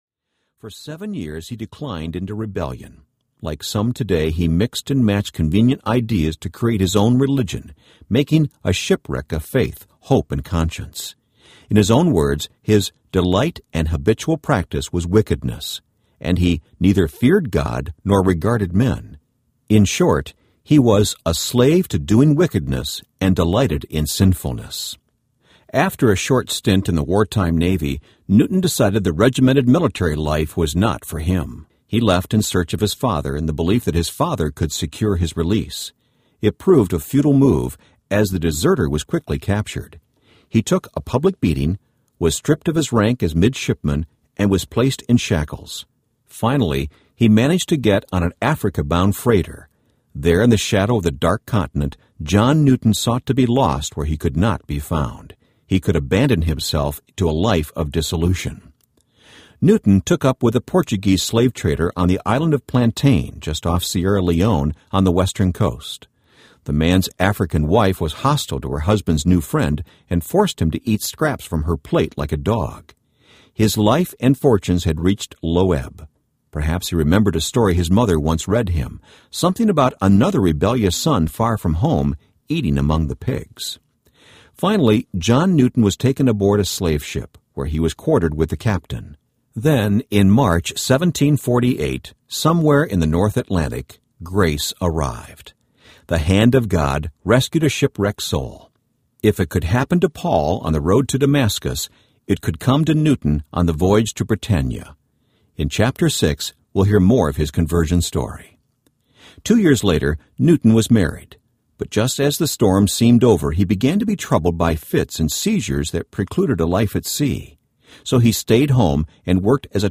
Captured by Grace Audiobook
5.65 Hrs. – Unabridged